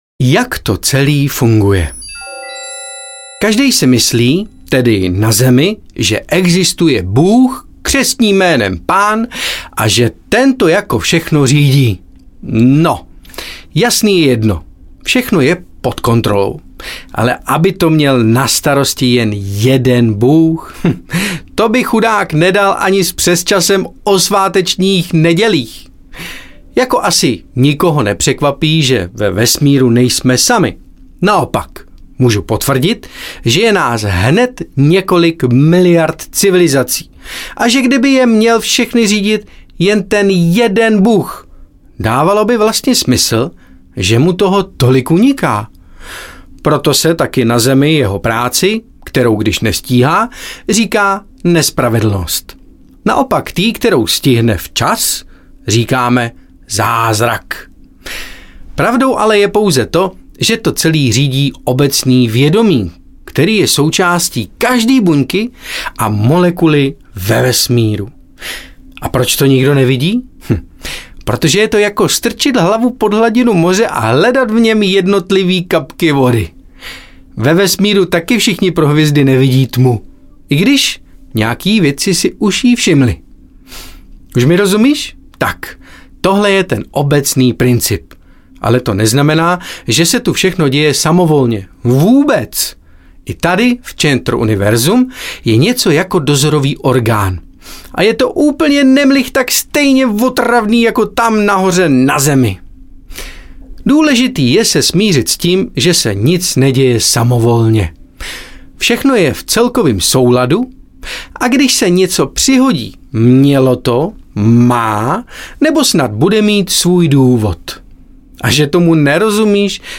Vydři - duši audiokniha
Ukázka z knihy